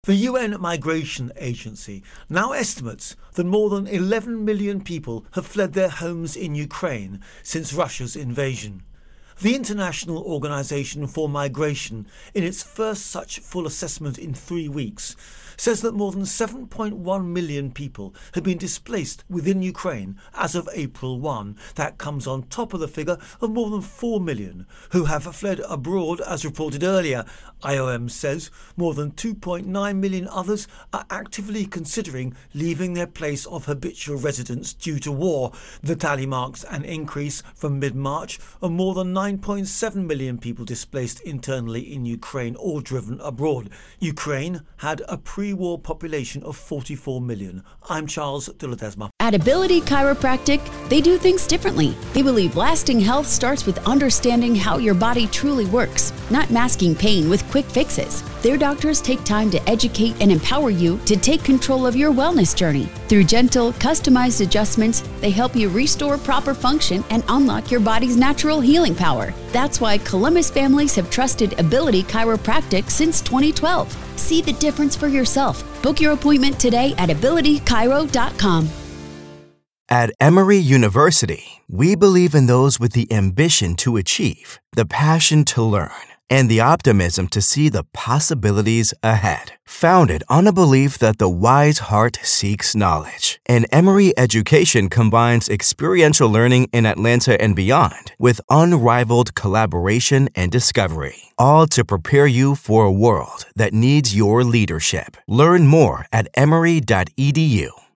Russia Ukraine War Migration Intro and Voicer